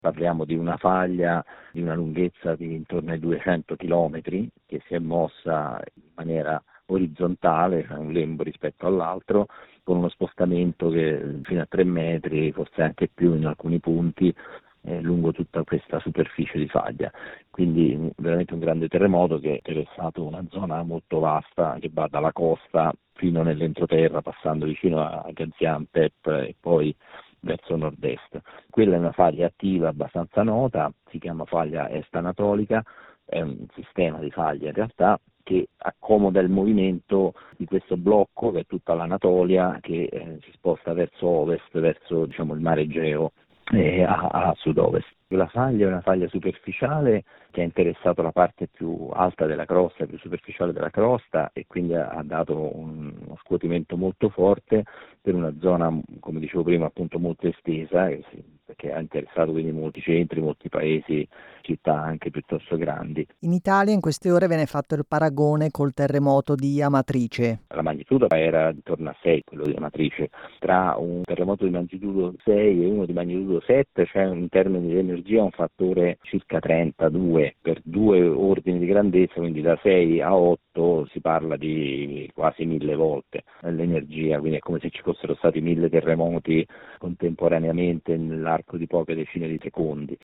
In termini di potenza il terremoto della scorsa notte è stato paragonato all’equivalente di 130 bombe atomiche. Sulle caratteristiche della scossa più devastante abbiamo intervistato